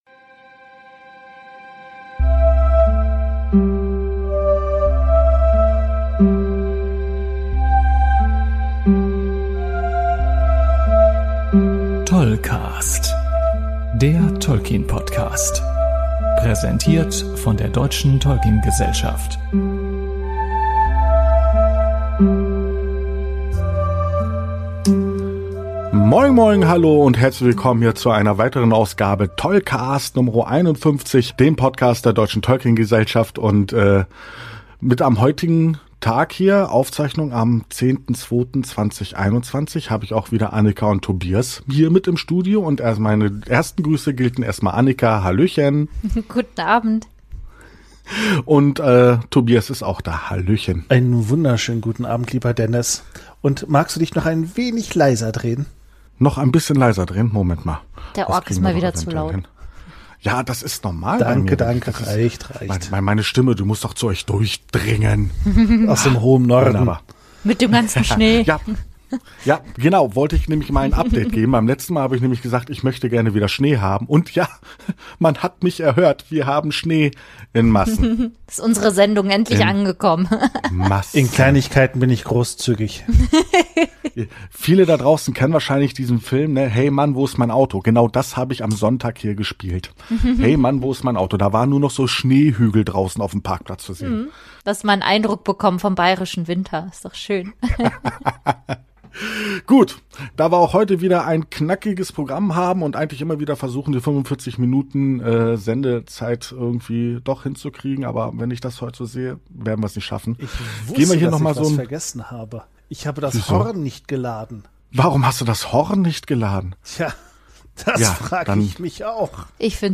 Die Drei vom TolkCast haben wieder richtig Spaß bei der äh..Arbeit. Der Ork ist mal wieder zu laut